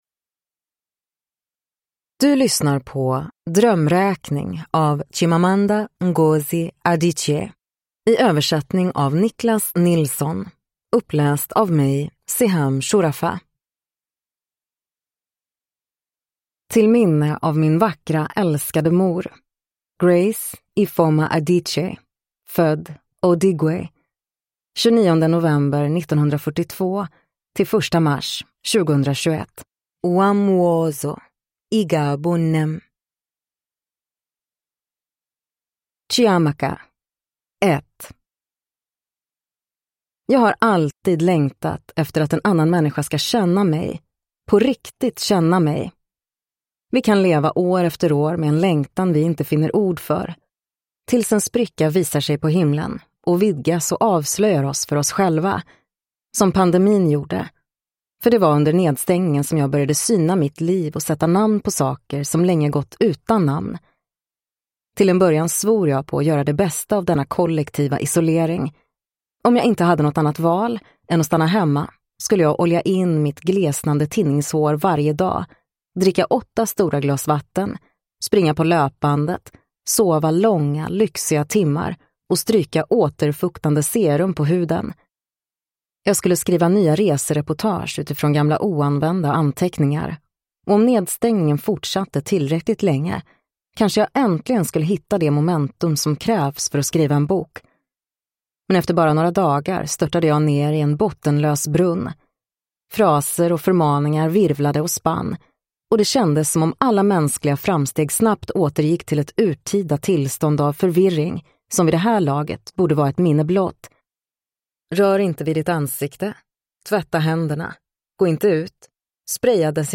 Drömräkning (ljudbok) av Chimamanda Ngozi Adichie